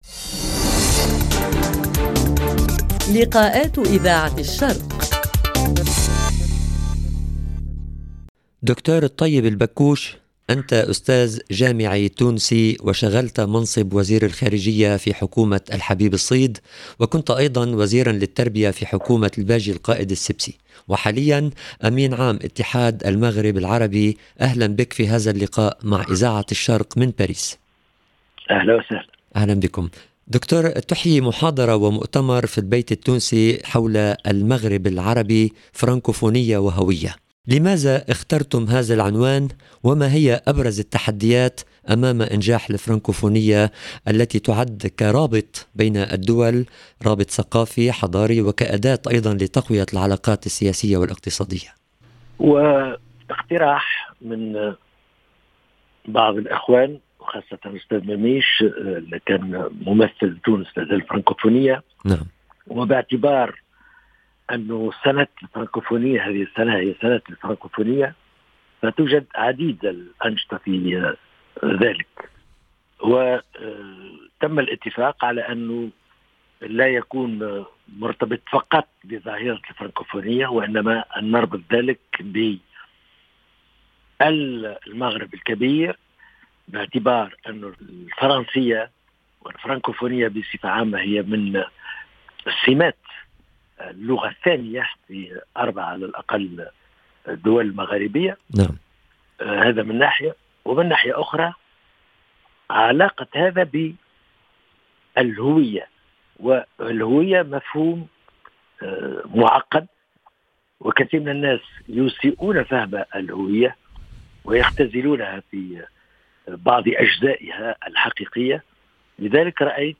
LIKAATS:Avec le secrétaire général de l’UMA et ancien ministre tunisien Taieb Baccouche